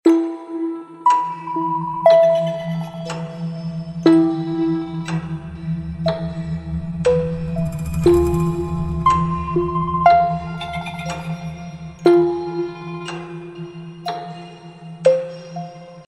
Horror Generative AI & Sound Sound Effects Free Download